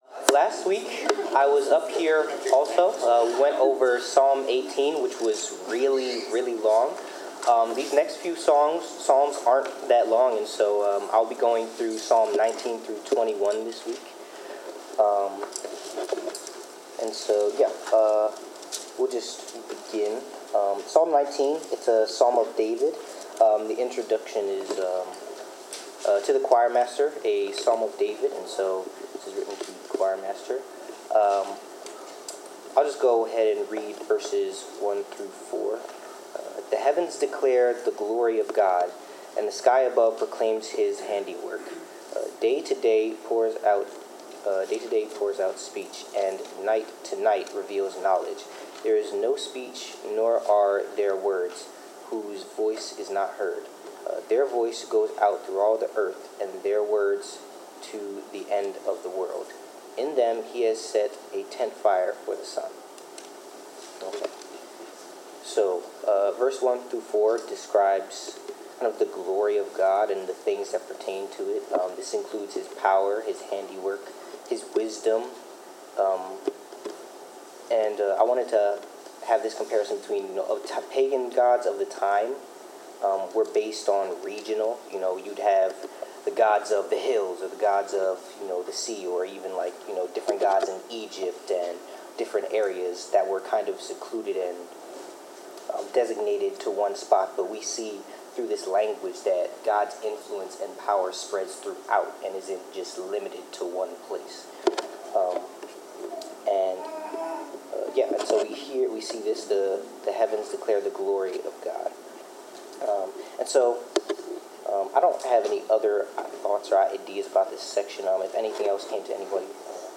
Bible class: Psalms 19-20
Service Type: Bible Class